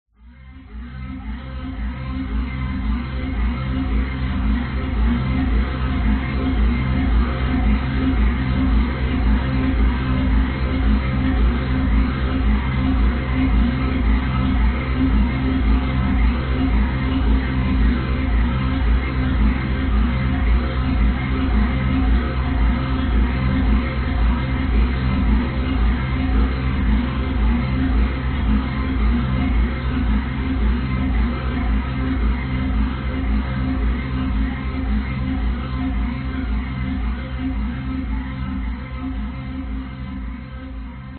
空间报警无人机
描述：空间警报器的低沉但突出的嗡嗡声。陌生的外星人窃窃私语和喋喋不休。
Tag: 环境 无人驾驶 电子 实验性 循环播放